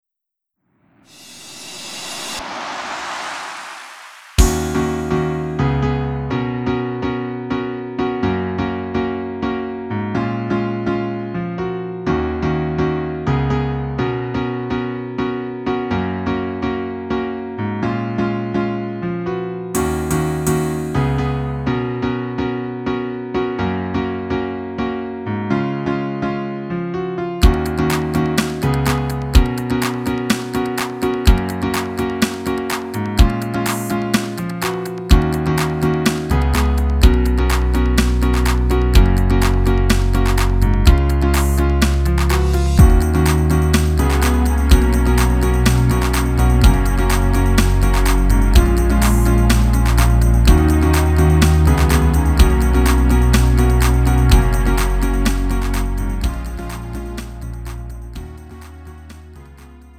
음정 원키 3:25
장르 구분 Lite MR